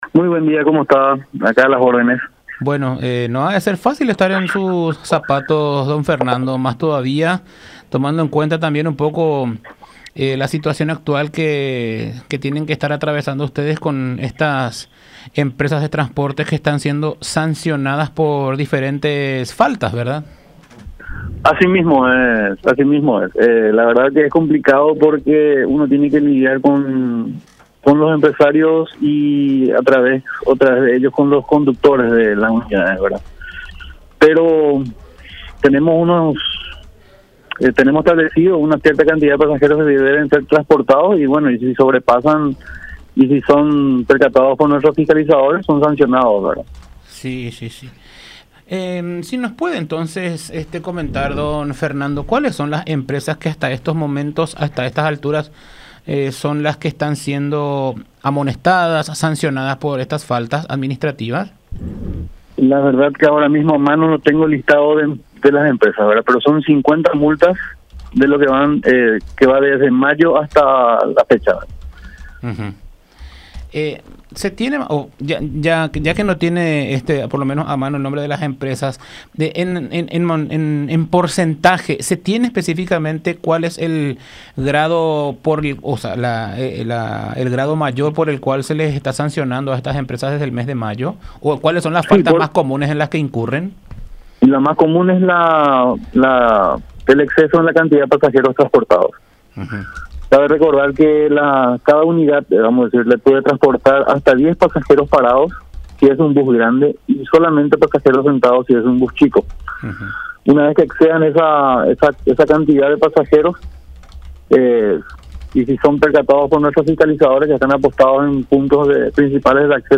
“y lo más común es el exceso de la cantidad de pasajeros transportados” señaló Fernando Haider en diálogo con La Unión R800 AM.